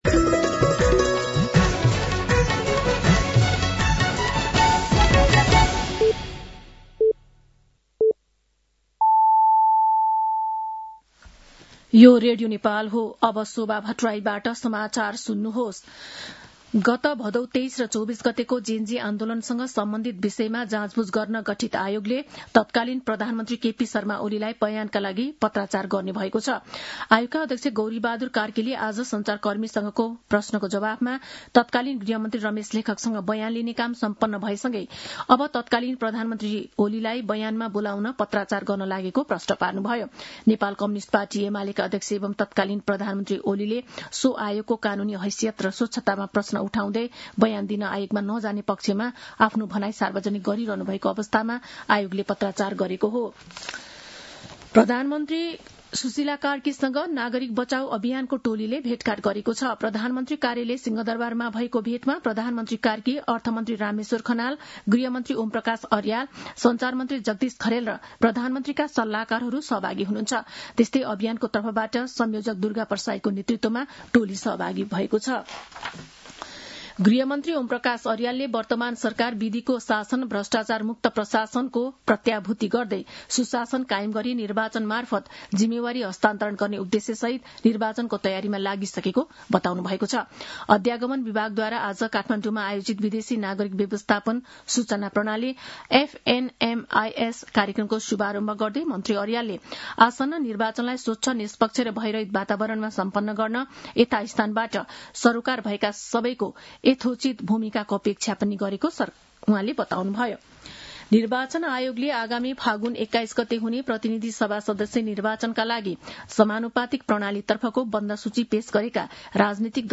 साँझ ५ बजेको नेपाली समाचार : १७ पुष , २०८२
5.-pm-nepali-news-.mp3